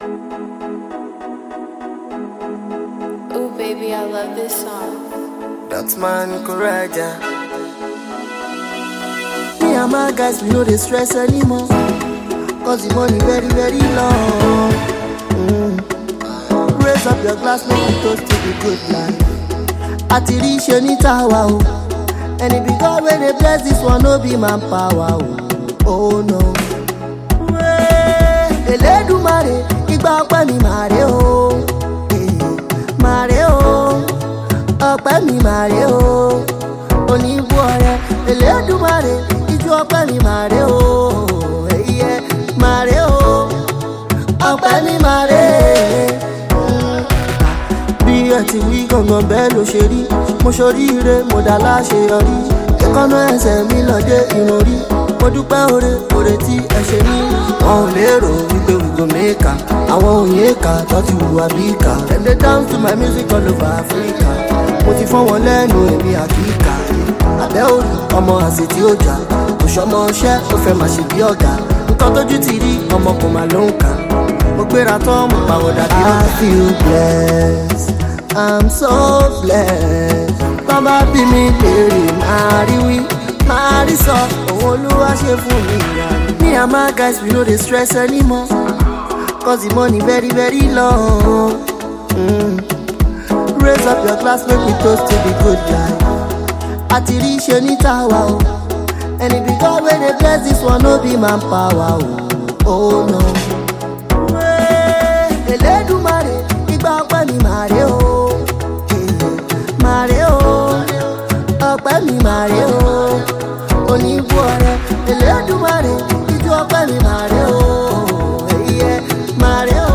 radio and club-friendly Jam